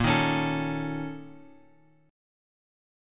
A# Minor Major 7th Double Flat 5th